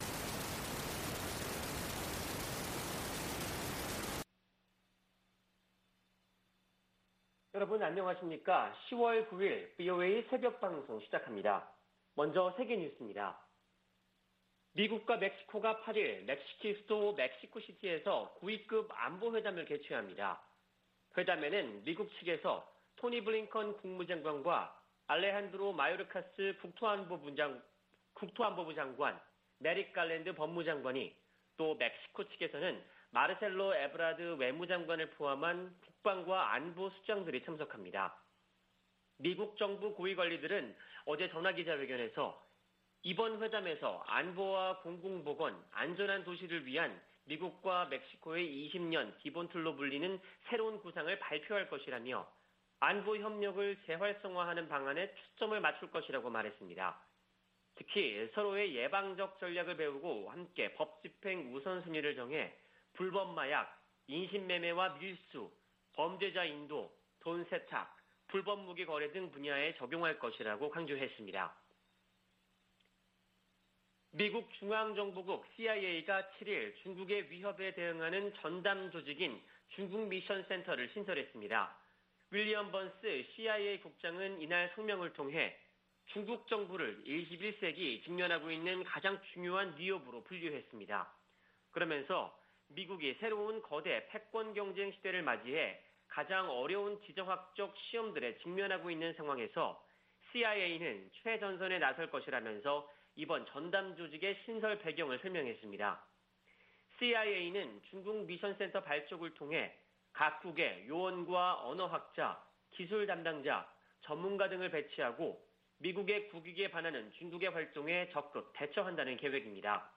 VOA 한국어 '출발 뉴스 쇼', 2021년 10월 9일 방송입니다. 코로나 방역 지원 물품이 북한에 도착해, 남포항에서 격리 중이라고 세계보건기구(WHO)가 밝혔습니다. 대북 인도적 지원은 정치 상황과 별개 사안이라고 미 국무부가 강조했습니다.